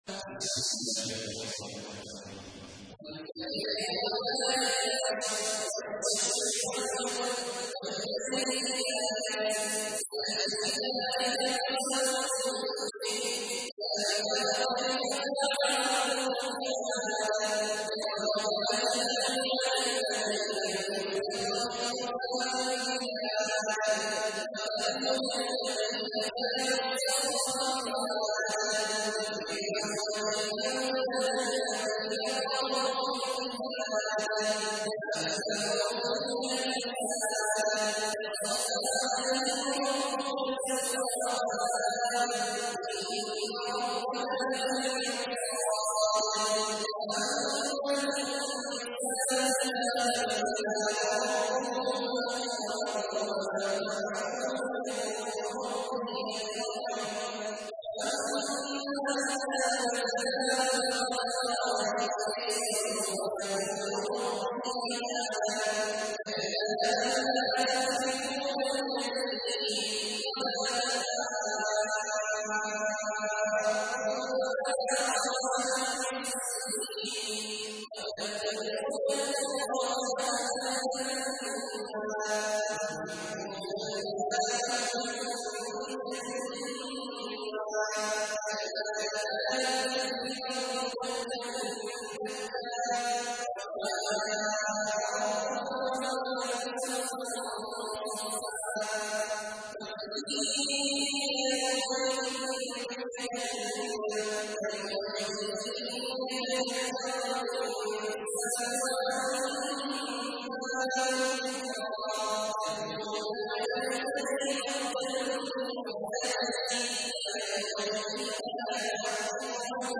تحميل : 89. سورة الفجر / القارئ عبد الله عواد الجهني / القرآن الكريم / موقع يا حسين